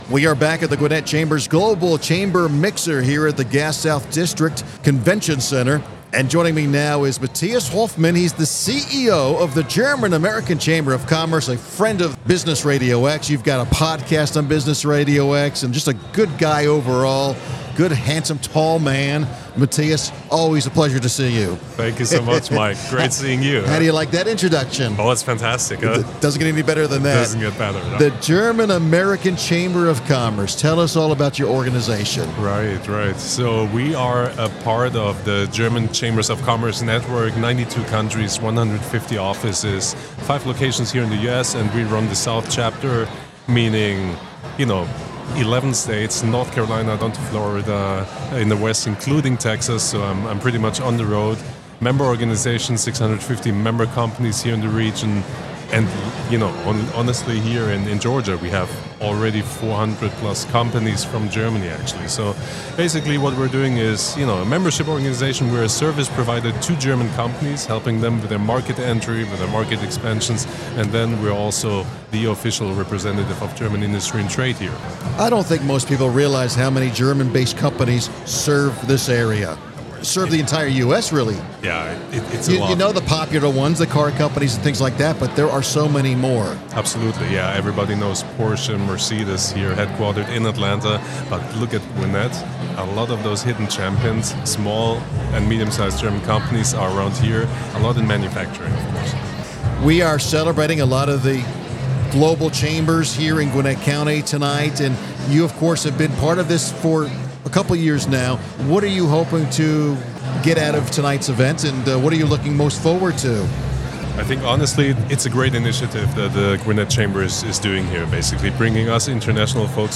The Gwinnett Chamber of Commerce hosted it’s 2024 Global Chamber Mixer on November 7 at the Gas South Convention Center in Duluth, GA.